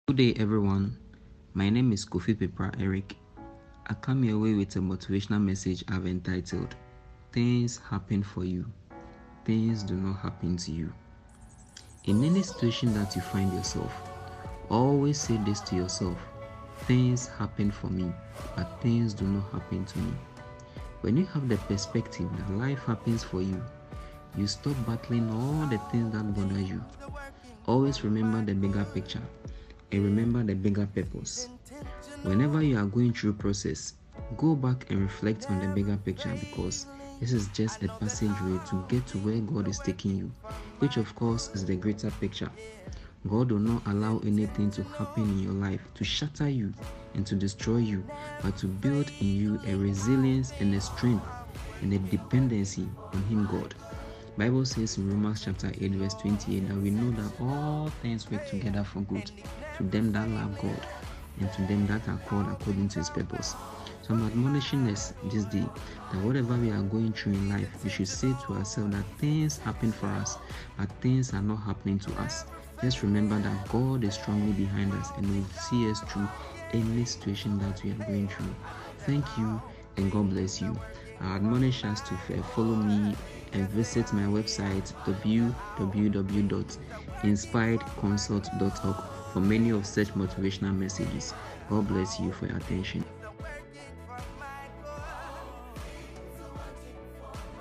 Disclaimer: we do not own rights to the background music.